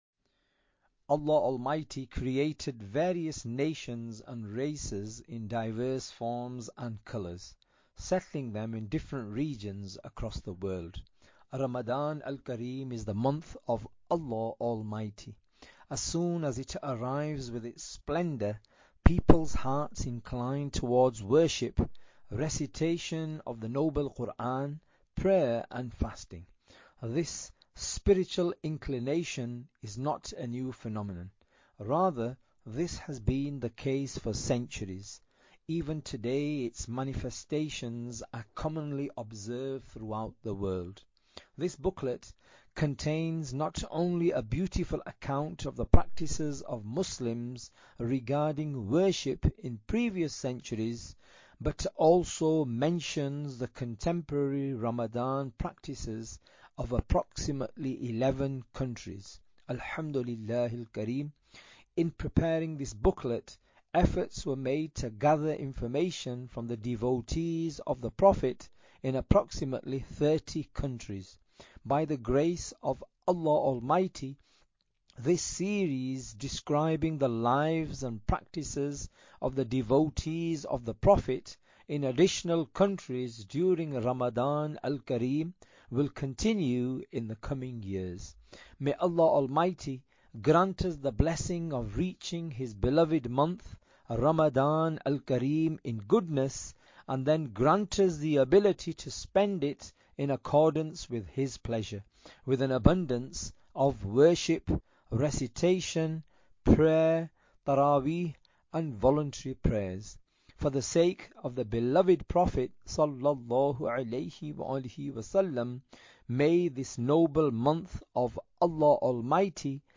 Audiobook - Delightful Ways Of Celebrating Ramadan al Karim (English)